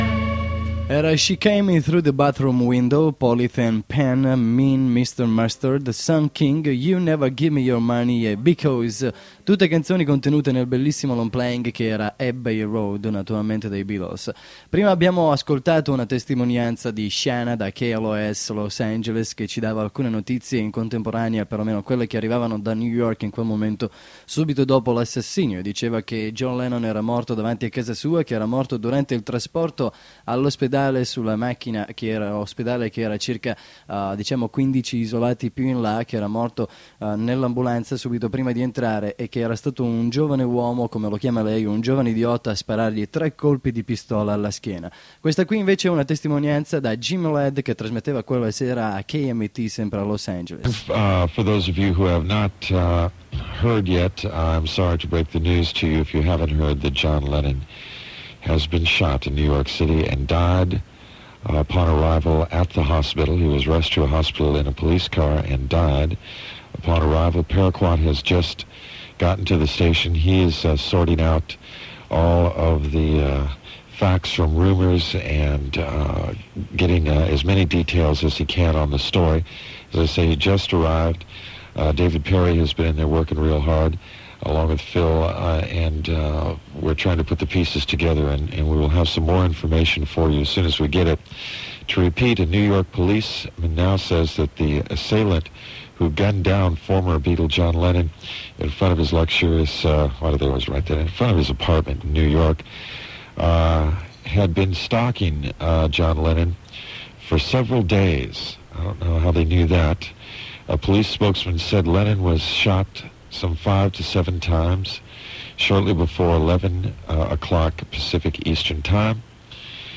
registrazione da KMET Los Angeles, con un intervento di Jim Ladd